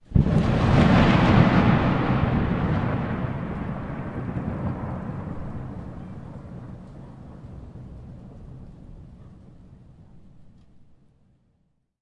雷霆
描述：雷霆用变焦h4nsp立体声录音机录制。
Tag: 雷电 暴雨 雷暴 天气 迅雷